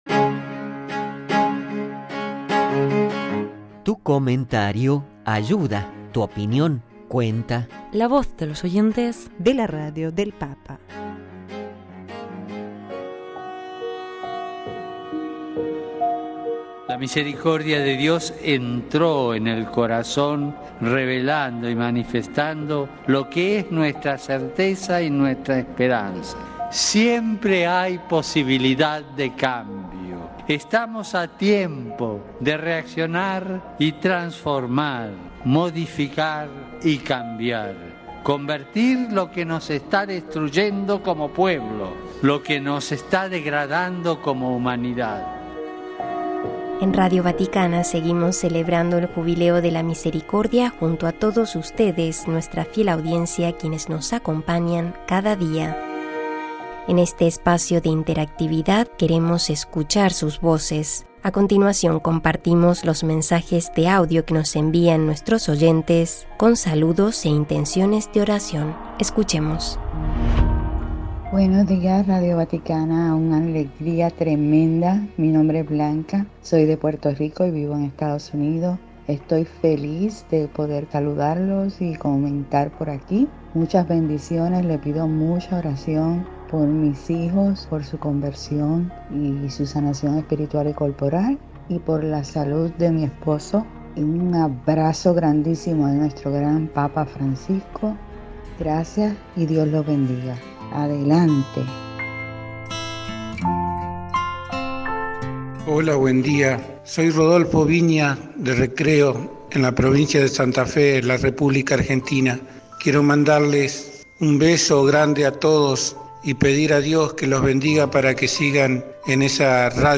«La Misericordia de Dios es nuestro escudo y fortaleza», la voz de nuestros oyentes